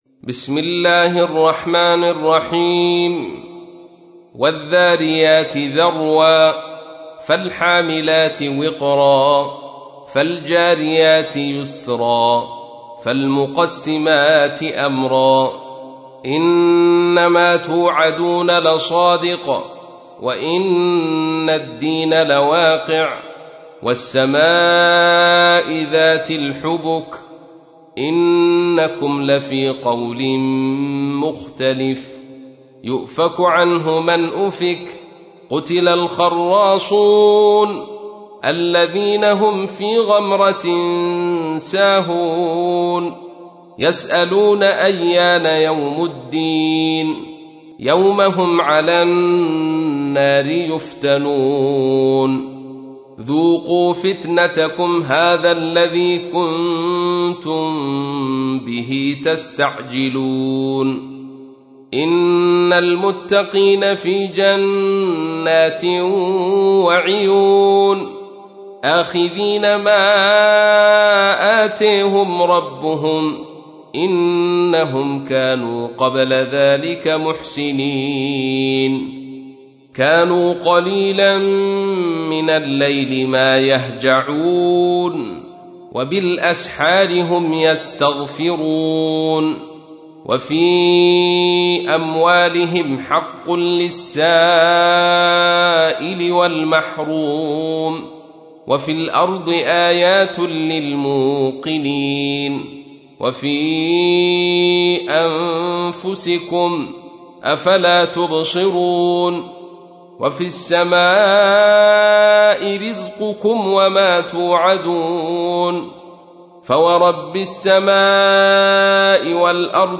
تحميل : 51. سورة الذاريات / القارئ عبد الرشيد صوفي / القرآن الكريم / موقع يا حسين